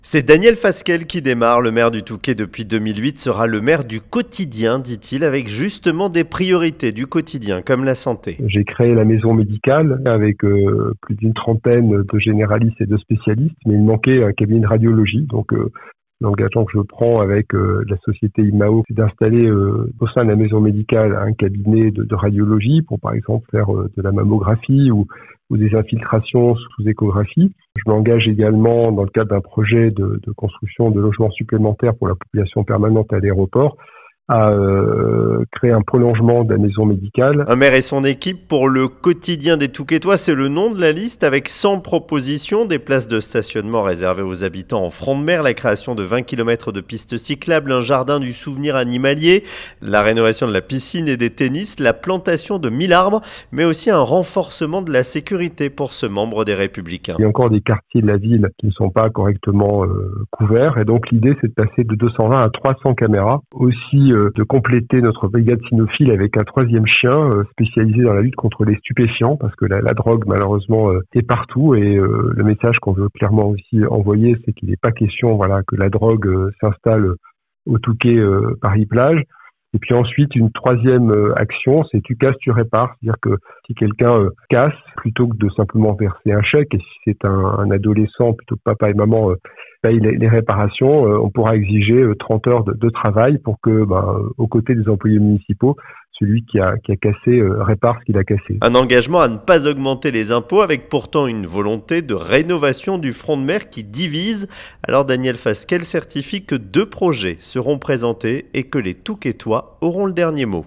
ENTRETIEN : Daniel Fasquelle veut être "le maire du quotidien" au Touquet